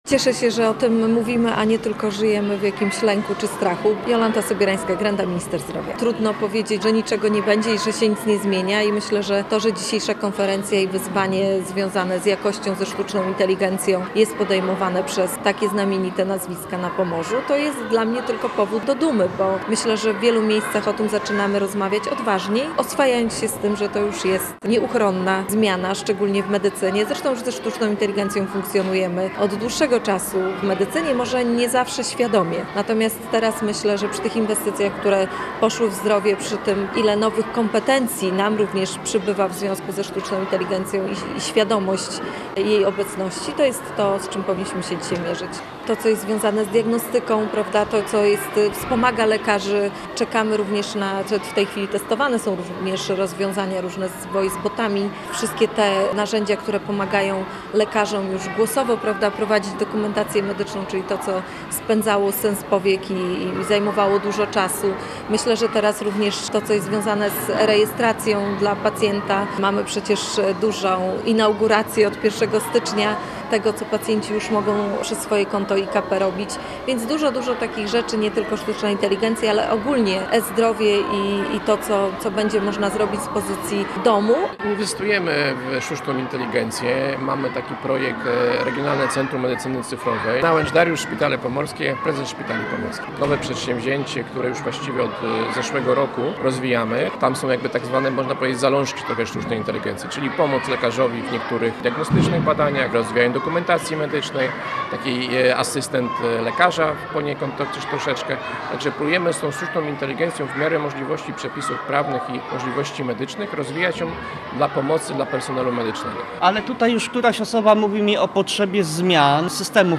Sztuczna inteligencja to przyszłość medycyny. Tak o nowej technologii mówili eksperci z ochrony zdrowia na branżowej konferencji w Gdańsku.